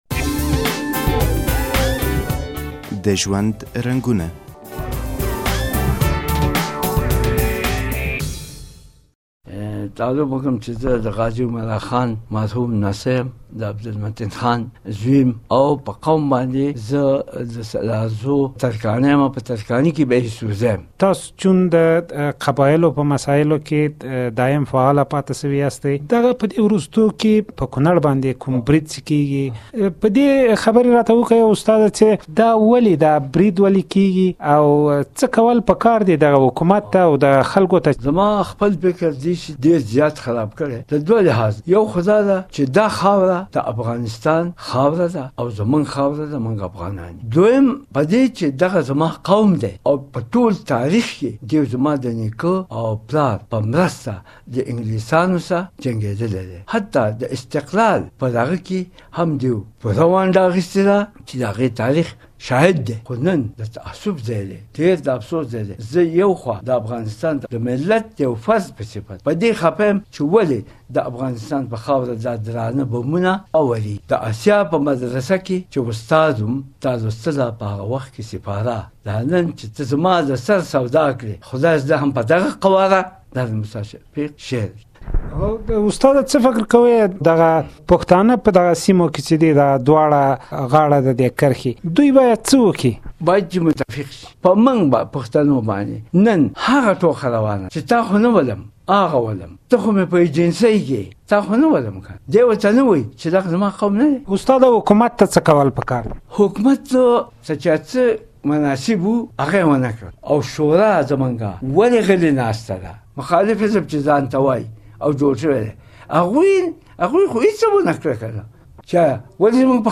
له یوه قبایلي قومي مشر سره مرکه